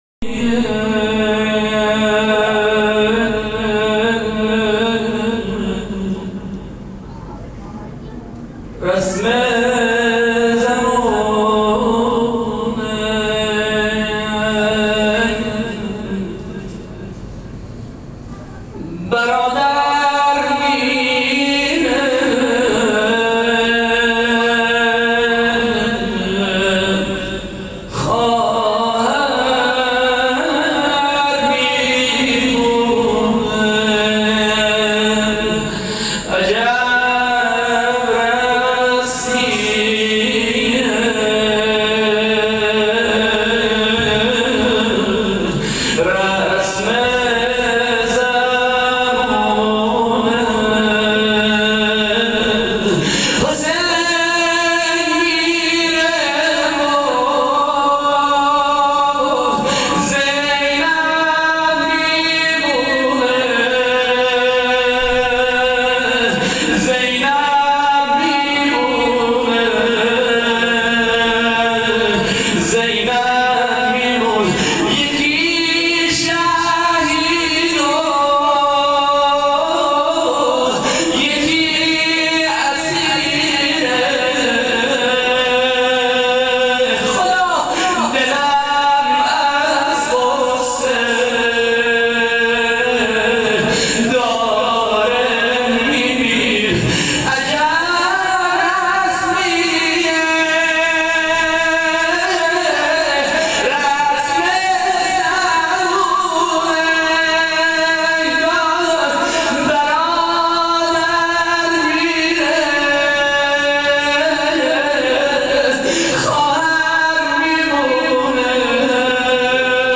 روضه حضرت زینب(س)